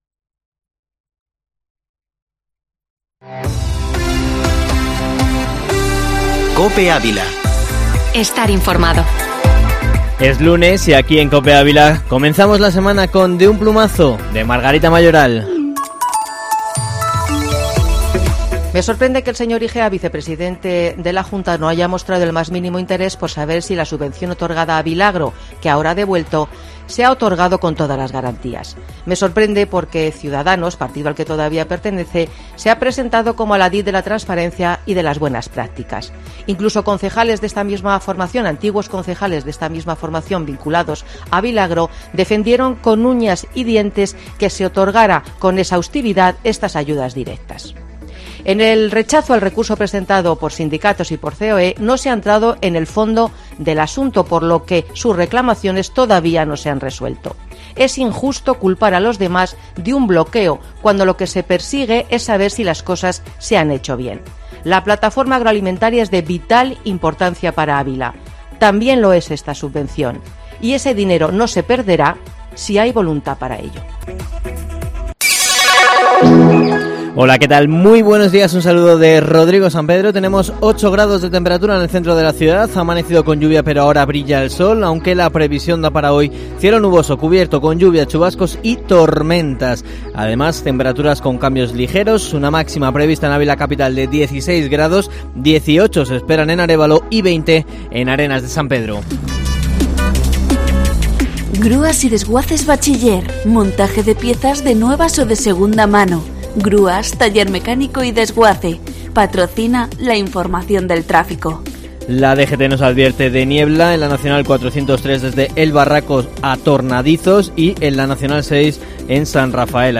Informativo Matinal Herrera en COPE Ávila 26/04/2021